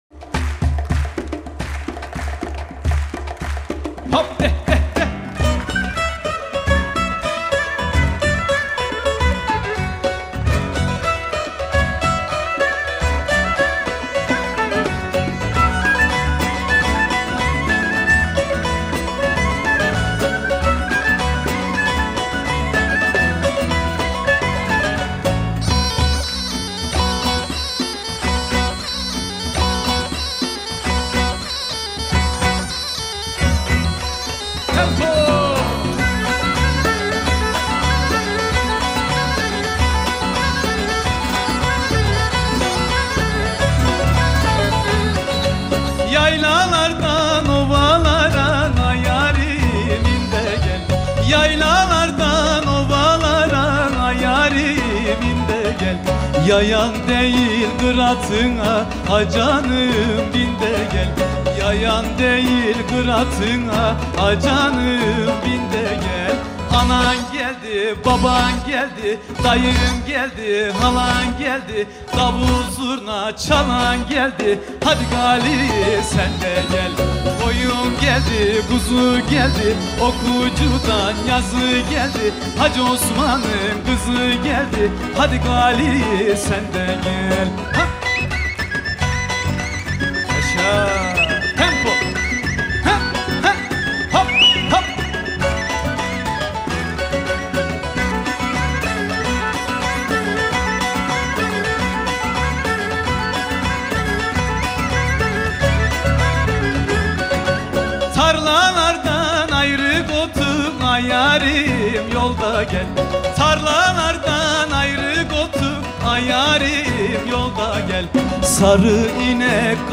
Türün en belirgin özelliği bağlamadaki çalış tavrı, sipsi, kabak kemane ile oyunlara eşlik olarak okunmasıdır.
Teke Zortlatması: Hadi Gari Sen de Gel
Hareketli bir yöresel türkü.